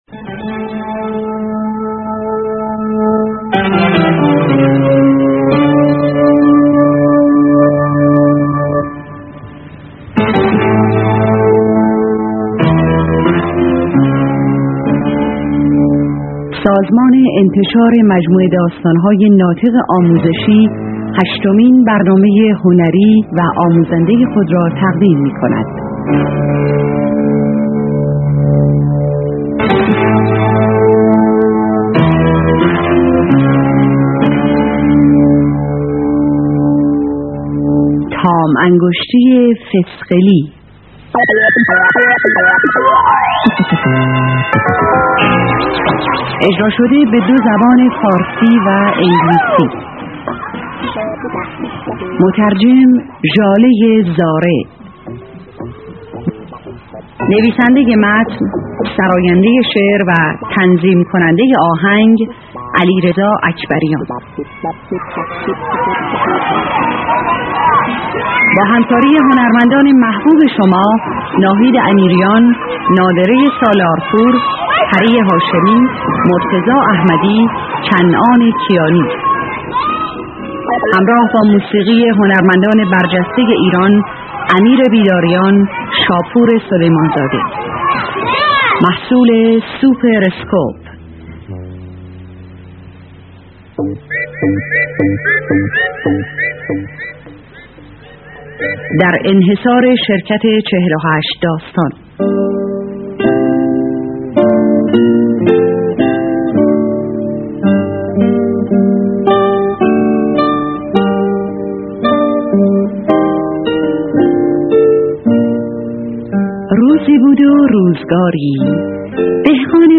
بچه های عزیز! شما می توانید همزمان با خواندن این قصه قشنگ، به فایل صوتی این قصه هم گوش بدید.